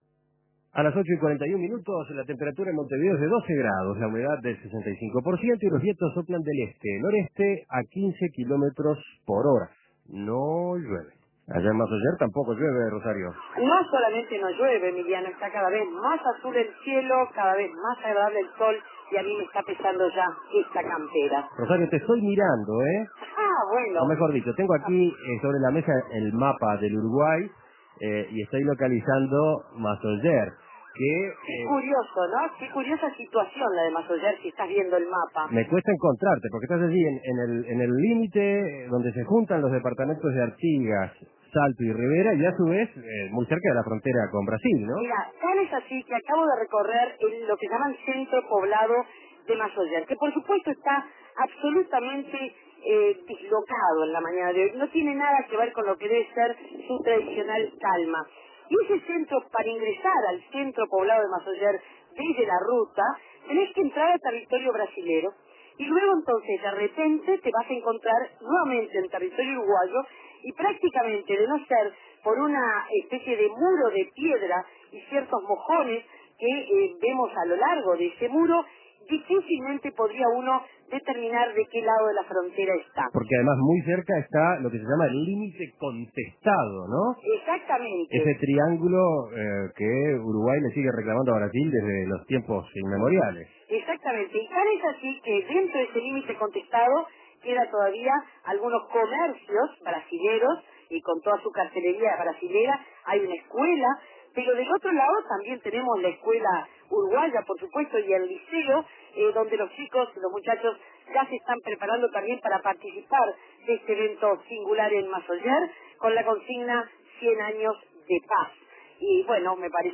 El centro poblado de Masoller en la jornada conmemorativa de los 100 años de la batalla donde cayó herido de muerte Aparicio Saravia.